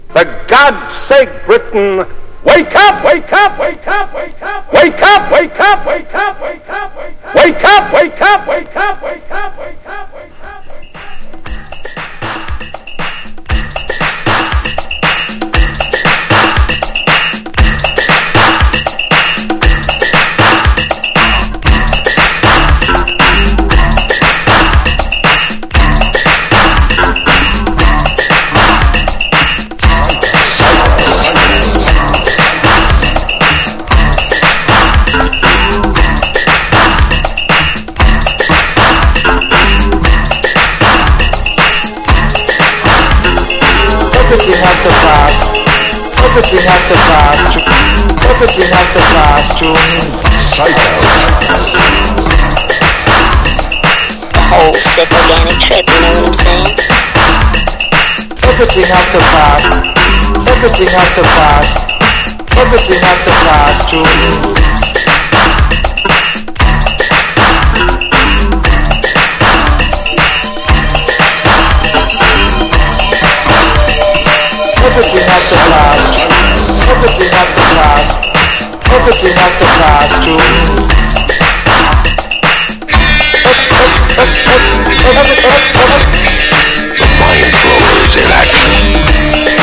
DEEP HOUSE / EARLY HOUSE# TECHNO / DETROIT / CHICAGO